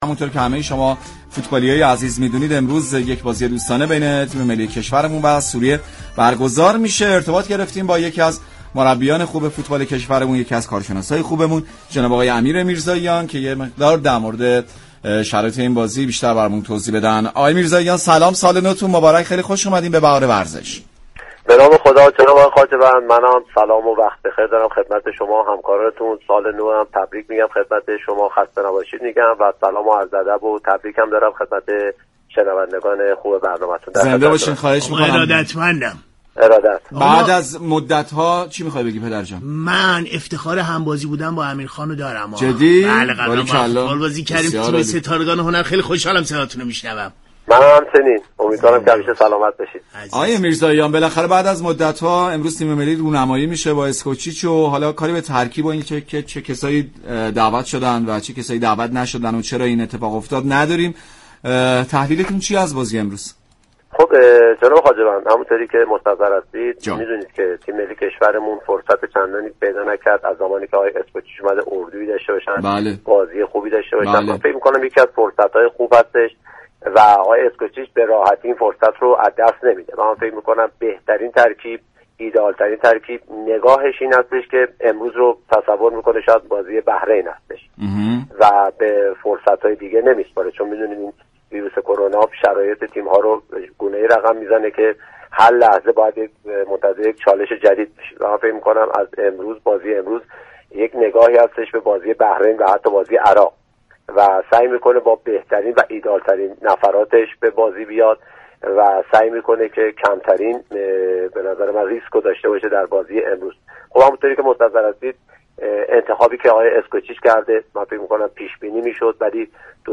شما می توانید از طریق فایل صوتی پیوست شنونده این گفتگو باشید